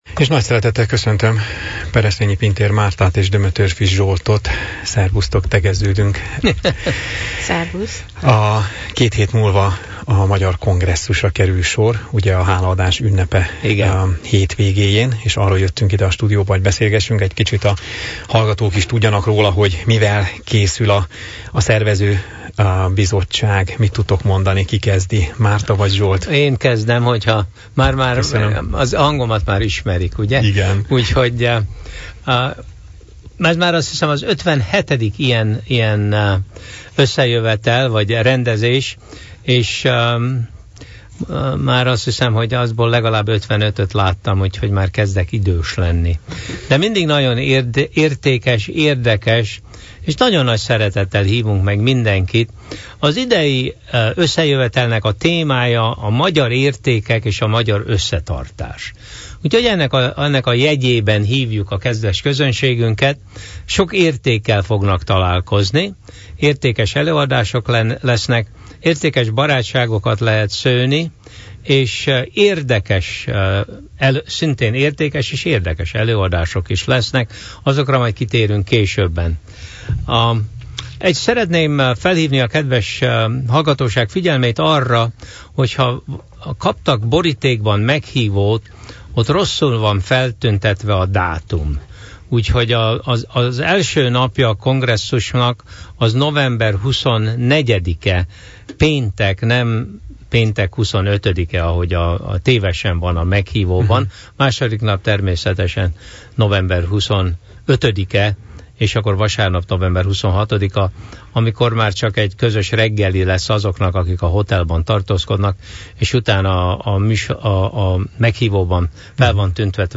Az alábbi linken vissza hallgatható a velük készült interjú, illetve a program nyomtatott változata is letölthető.